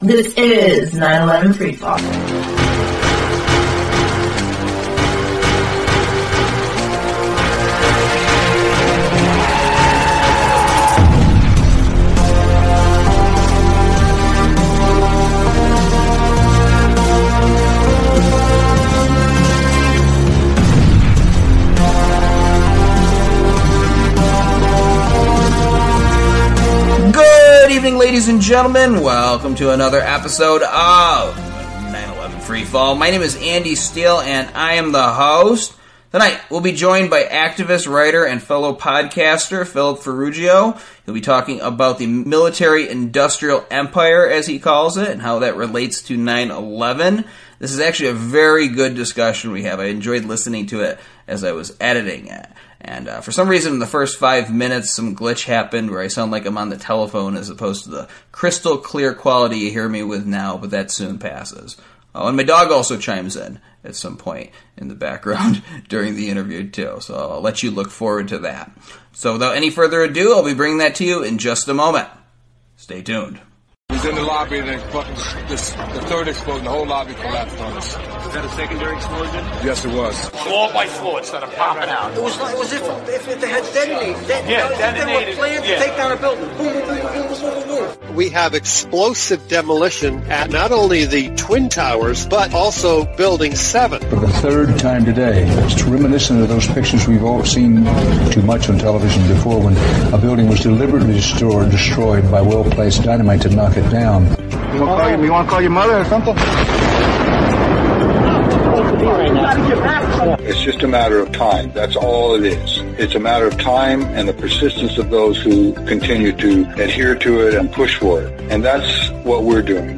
Each episode, we interview one of the many researchers and activists who are blowing the lid off the crime of the century.
Talk Show